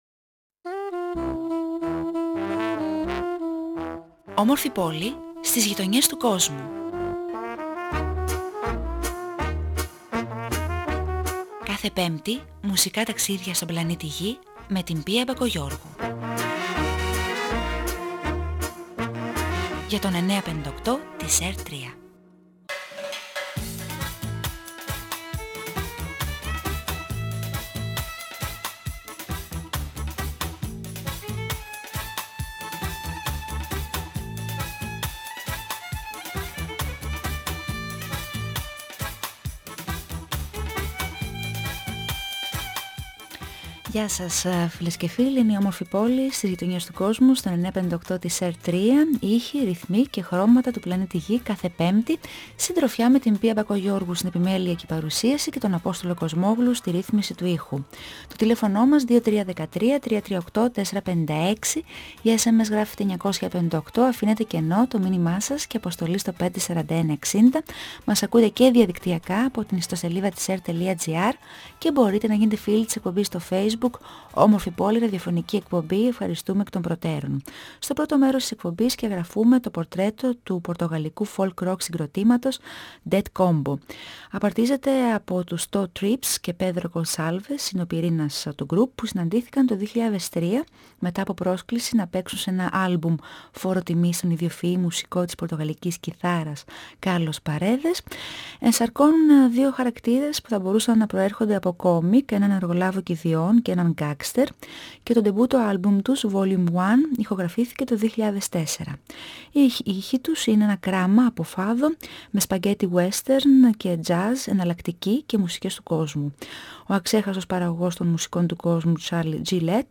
παντρεύει το fado με τη folk και τα spaghetti western